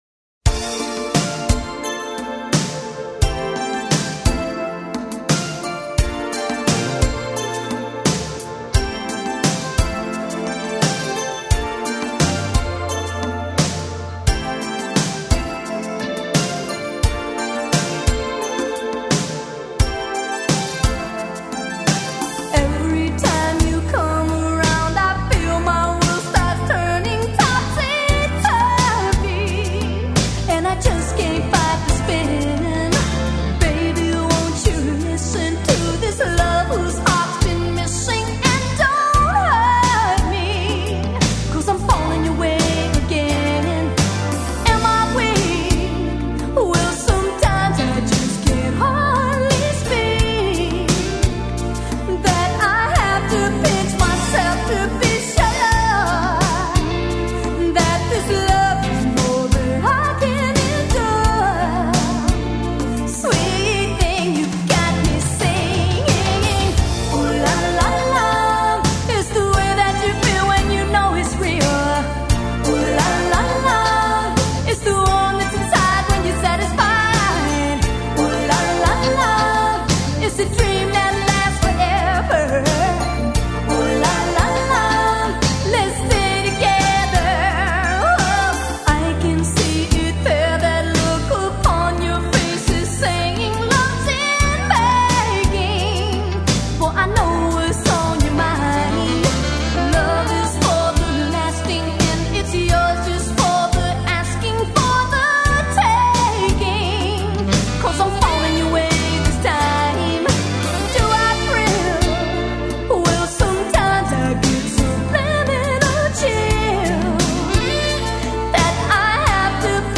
soulful and whimsical